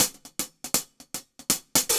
Index of /musicradar/ultimate-hihat-samples/120bpm
UHH_AcoustiHatB_120-04.wav